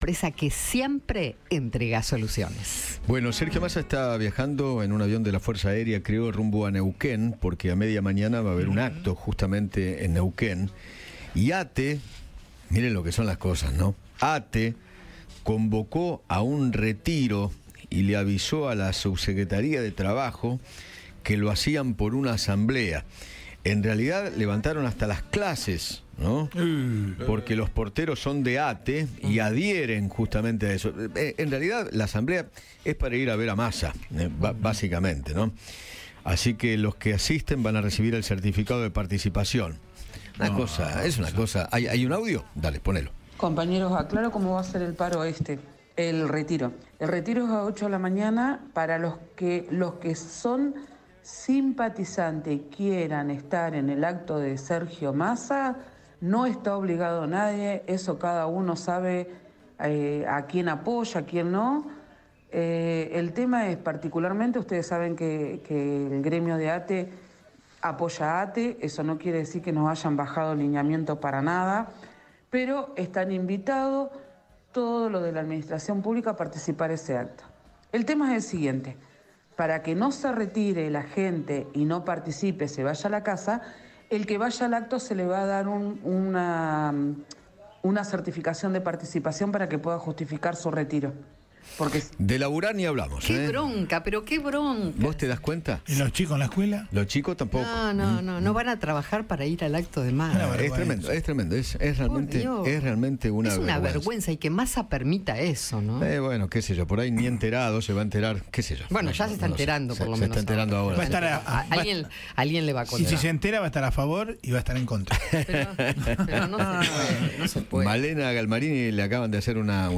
La bronca de Eduardo Feinmann contra un acto de ATE en apoyo a Sergio Massa: “De laburar no hablamos” - Eduardo Feinmann